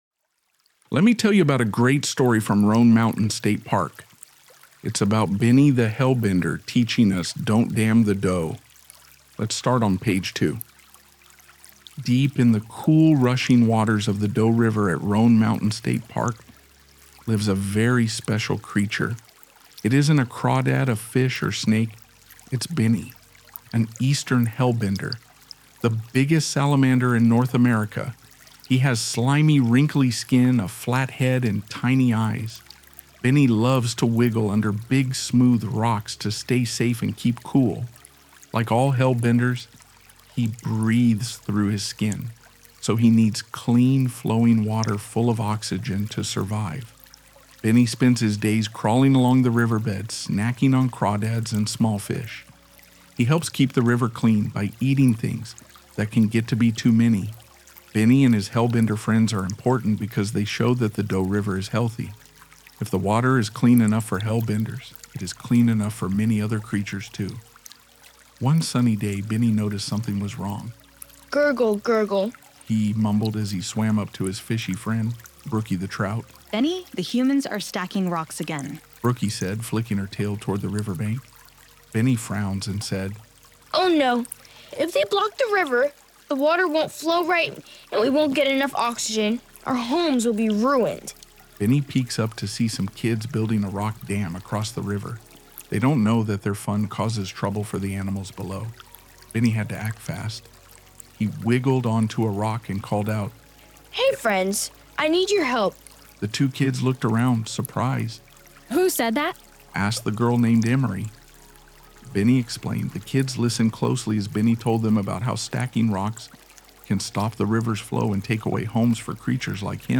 Download the audio companion and follow along in the book with the Narrator, Benny and his friends as they tell the story.